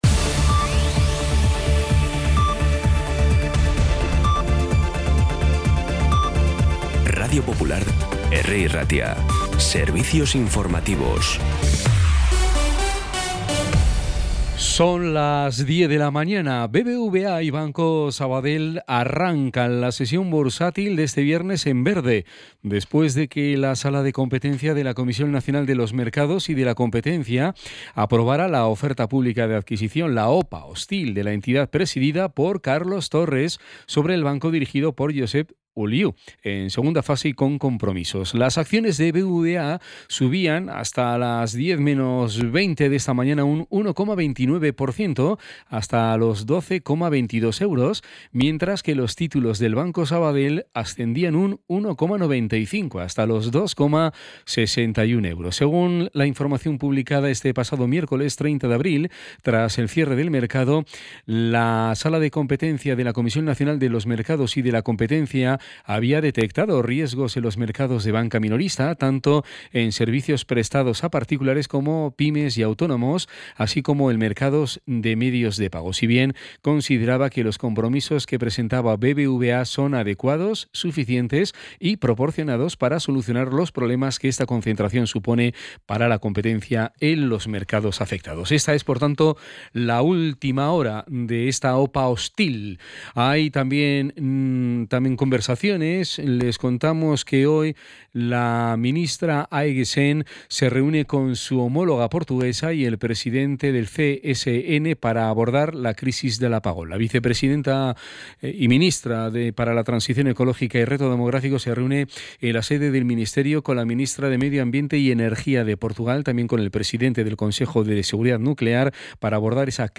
Las noticias de Bilbao y Bizkaia del 2 de mayo a las 10
Los titulares actualizados con las voces del día. Bilbao, Bizkaia, comarcas, política, sociedad, cultura, sucesos, información de servicio público.